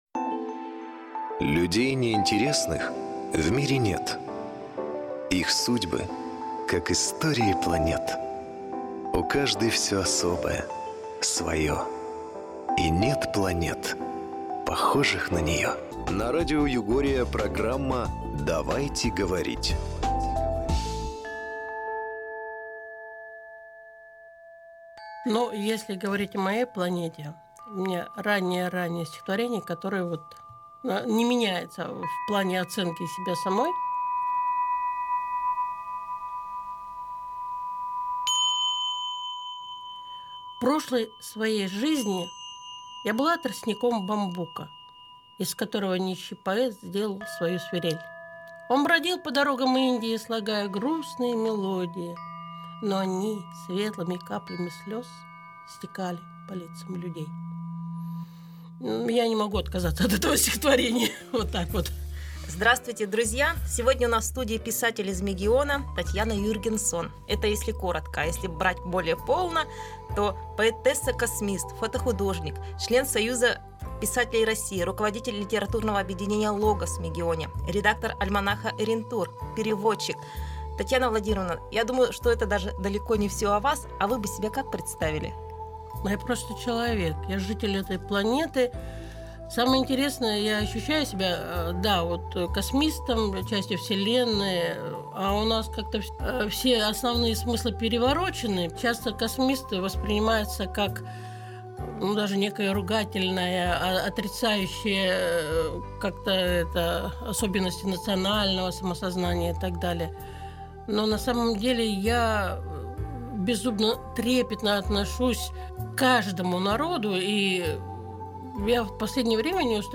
Озвучено Ai песни или озвученные стихи